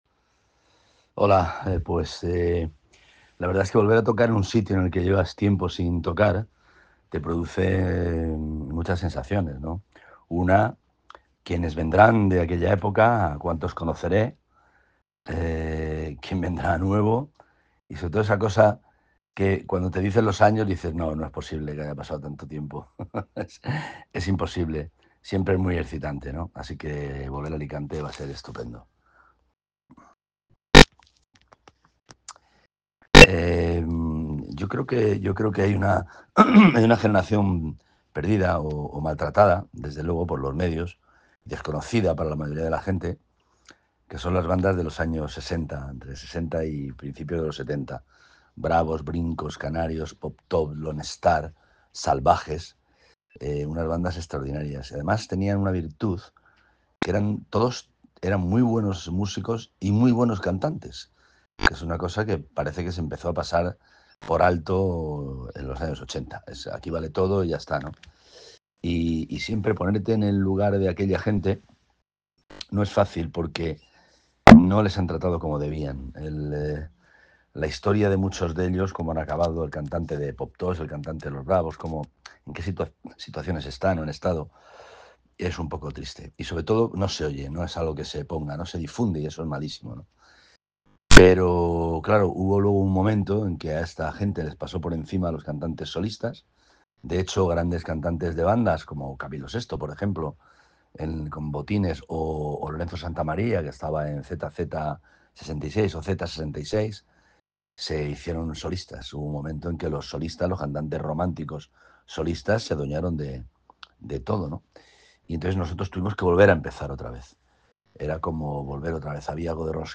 Entrevista en exclusiva a Ramoncín: "Viví en Benalua con mi novia alicantina"